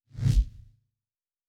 pgs/Assets/Audio/Sci-Fi Sounds/Weapons/Lightsaber 2_1.wav at master
Lightsaber 2_1.wav